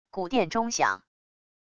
古琔钟响wav音频